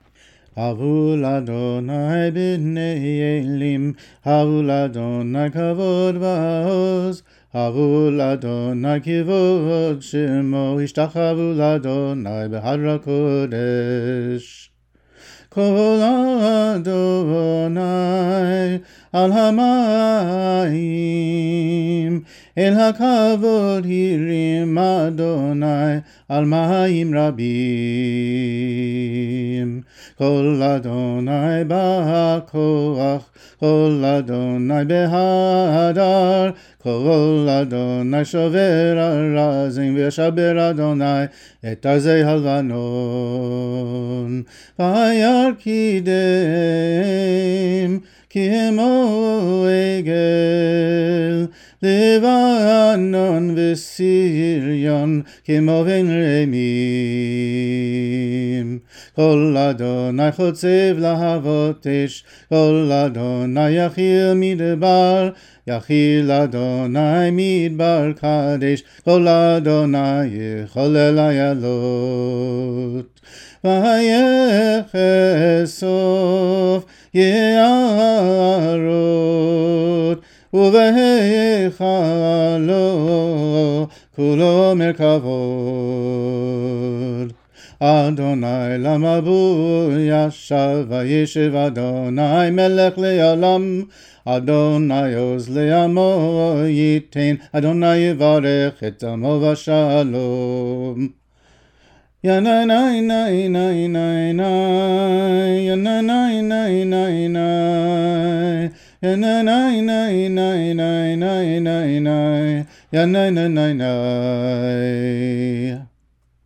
This melody is pretty straightforward Jewish-sounding, but its rhythms specifically fit the pattern of the Psalm.
Havu-sung.mp3